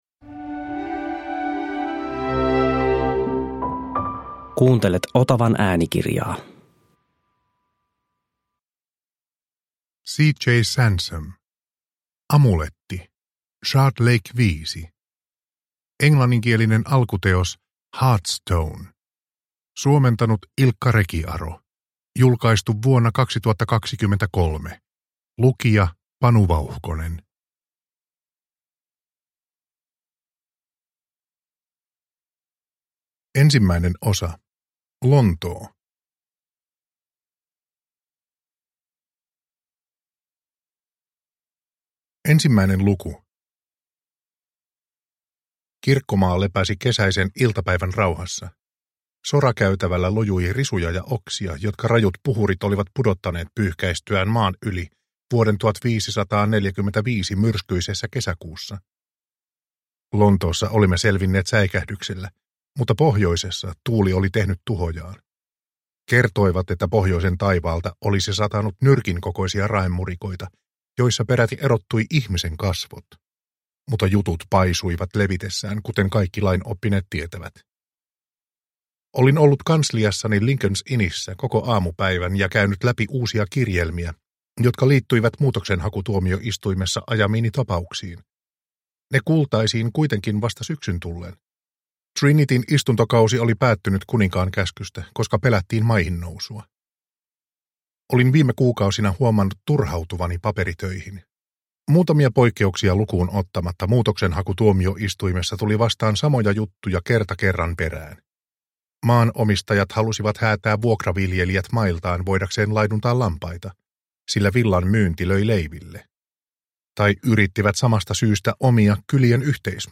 Amuletti – Ljudbok – Laddas ner